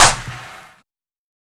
SouthSide Clap (21).wav